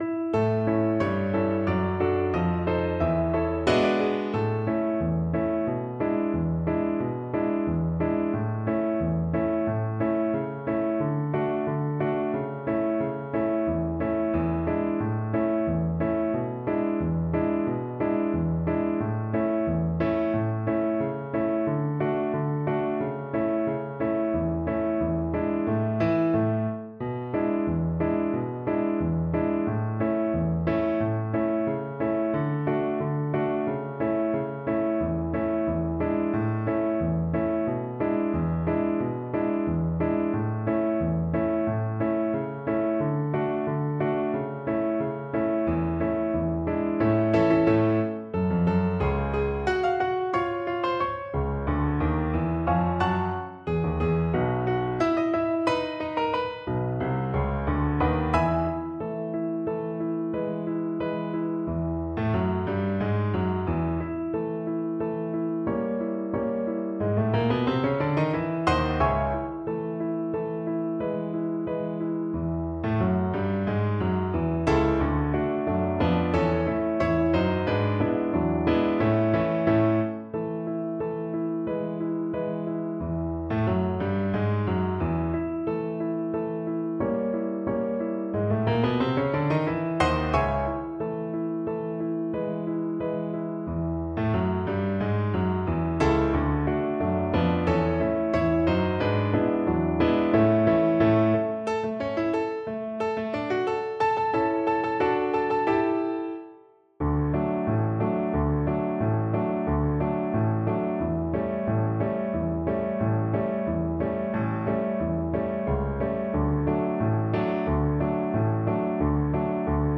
Play (or use space bar on your keyboard) Pause Music Playalong - Piano Accompaniment transpose reset tempo print settings full screen
A major (Sounding Pitch) (View more A major Music for Violin )
= 180 Andante
Jazz (View more Jazz Violin Music)